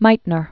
(mītnər), Lise 1878-1968.